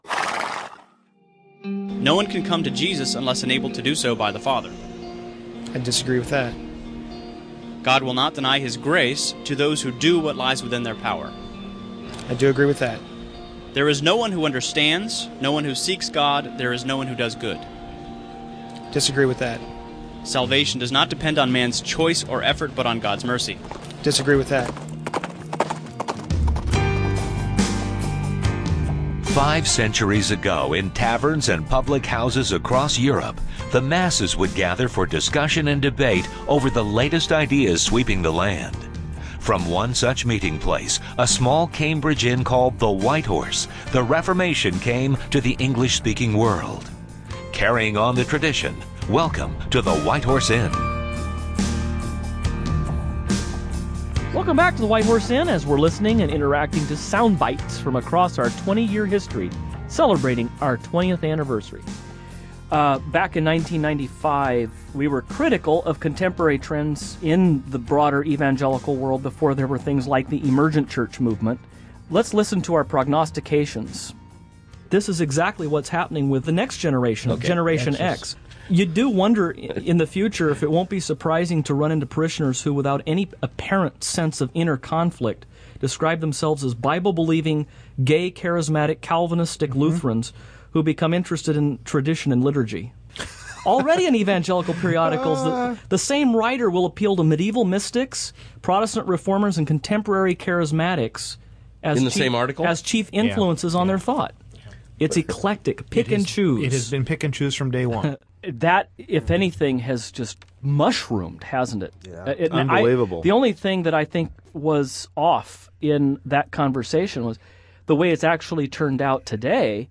As part two of our special 20th anniversary celebration, this program features more soundbites from across the decades, including some highlights from our old "Inventory of Relics" segment, various man-on-the-street interviews, and White Horse Inn spoof commercials.